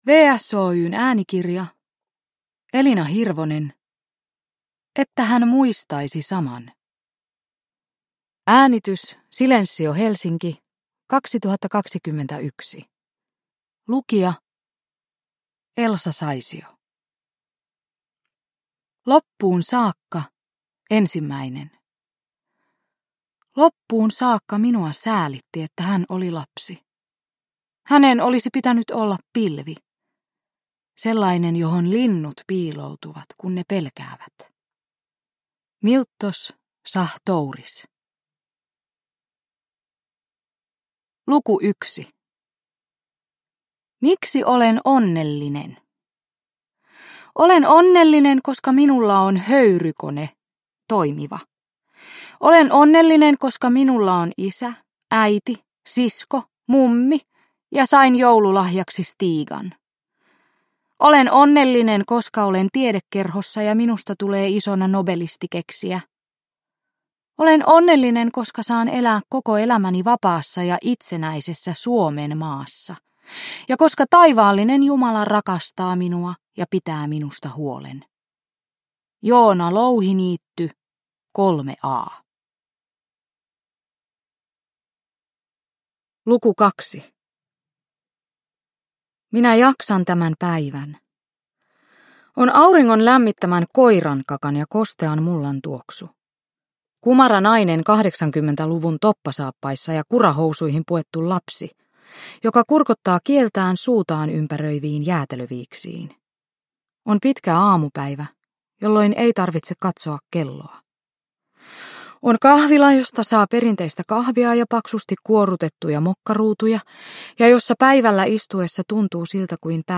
Että hän muistaisi saman – Ljudbok – Laddas ner